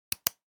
click-234708.ogg